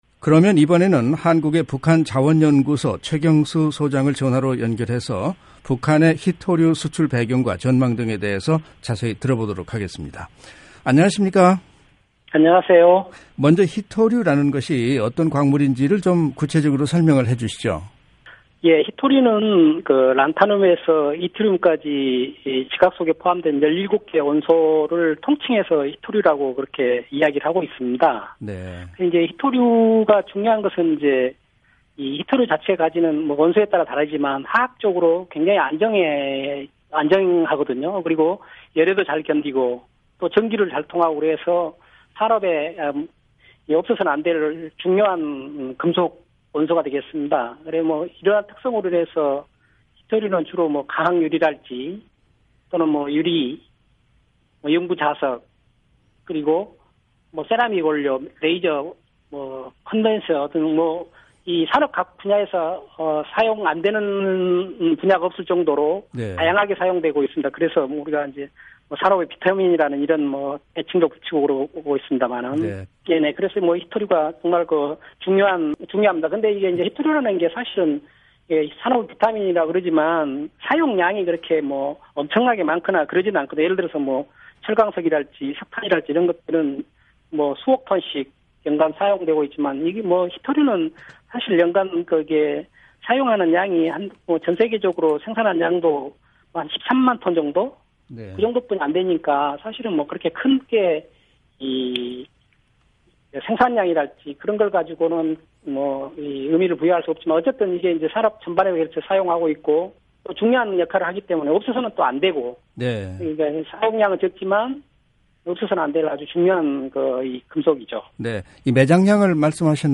[인터뷰 오디오 듣기] 북한, 희토류 수출 전망